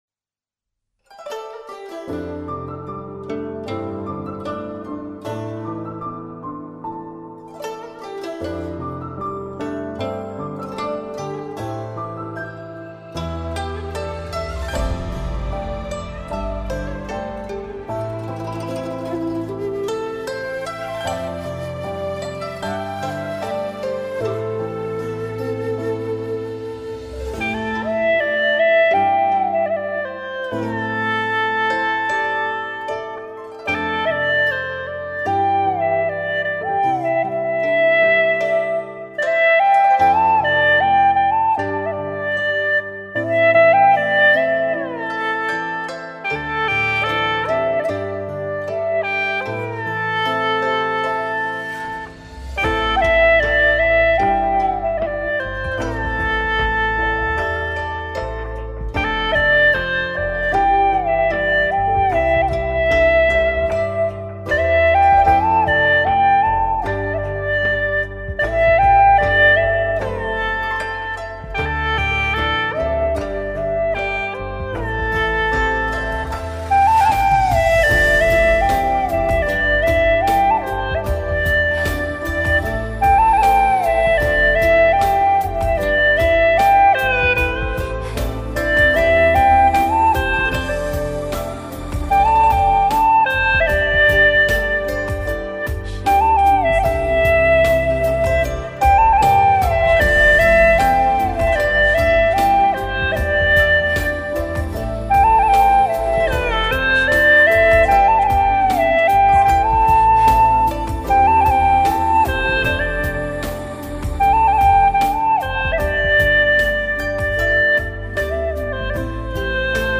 调式 : C 曲类 : 古风